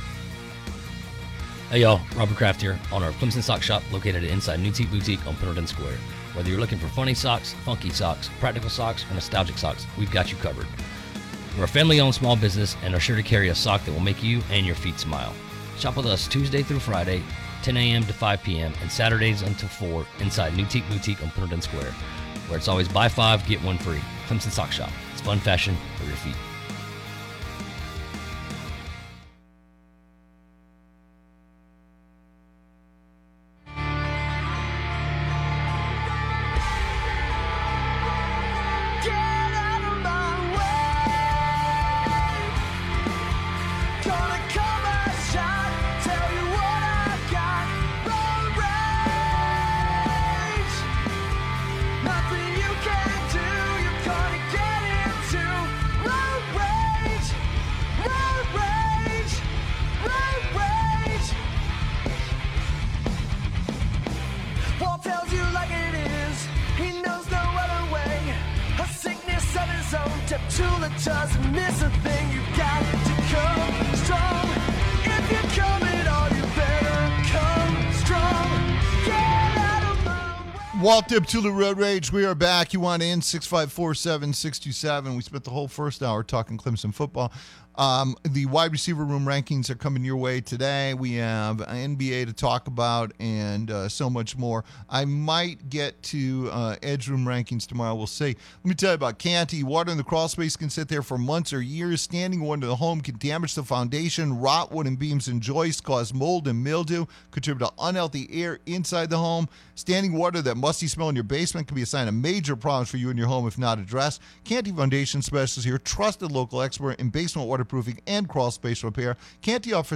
He brings his fiery and passionate personality to the airwaves every day, entertaining listeners with witty comments, in depth analysis and hard-hitting interviews.